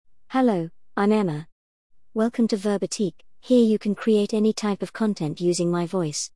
FemaleBritish English
Emma — Female British English AI voice
Emma is a female AI voice for British English.
Voice sample
Listen to Emma's female British English voice.
Emma delivers clear pronunciation with authentic British English intonation, making your content sound professionally produced.